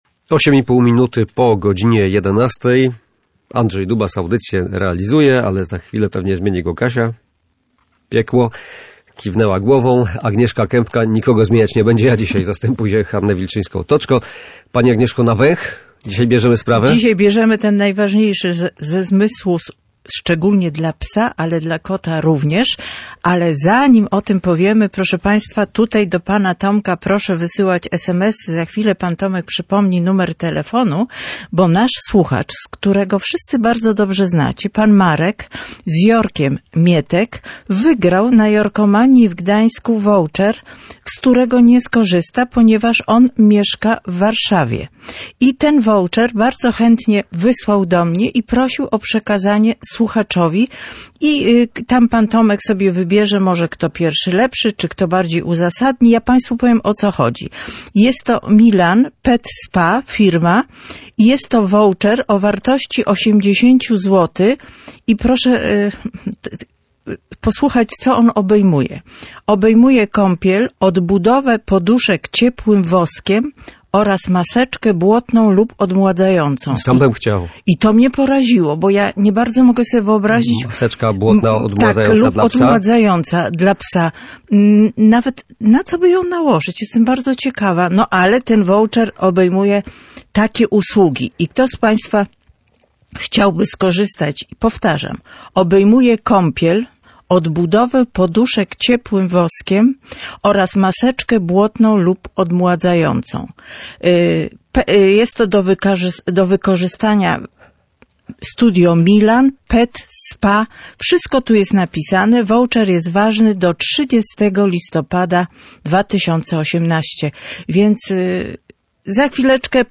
W audycji Psie porady kontynuowaliśmy temat zmysłów występujących u naszych pupili. Tym razem skupiliśmy się na węchu – najważniejszym zmyśle dla psów i kotów.